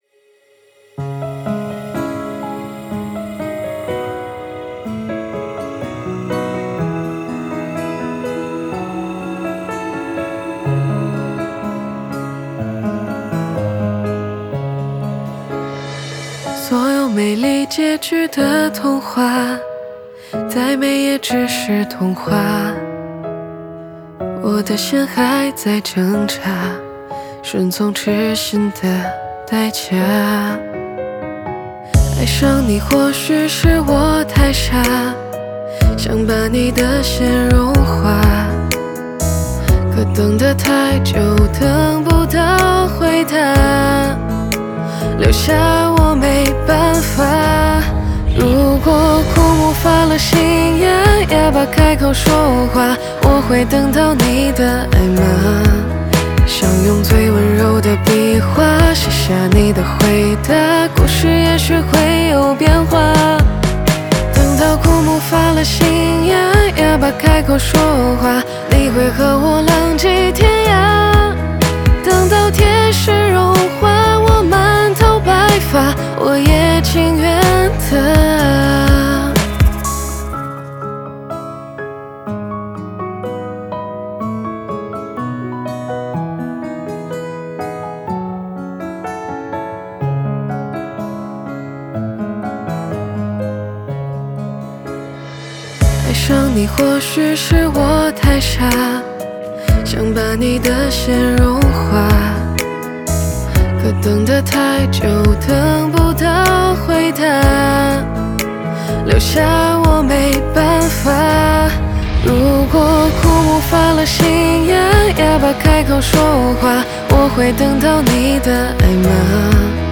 Ps：在线试听为压缩音质节选，体验无损音质请下载完整版
和声harmony
吉他Guitar